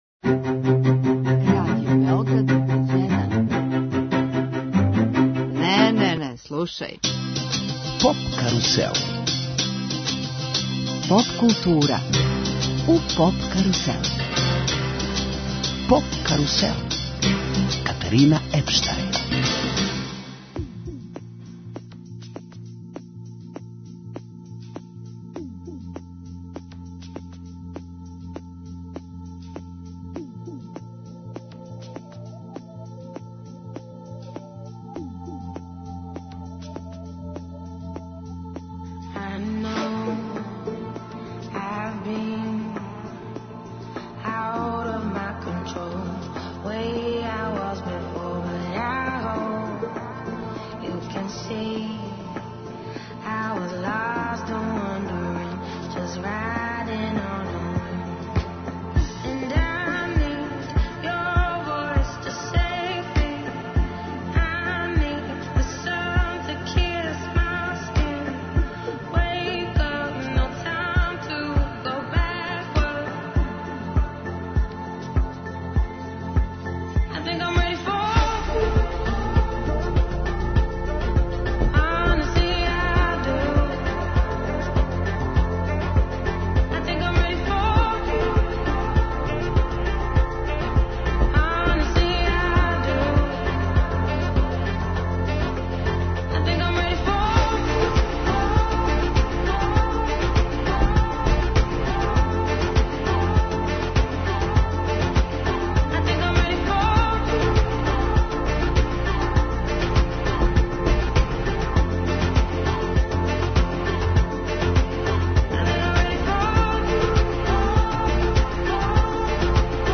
Гошћа емисије је певачица Ана Ђурић.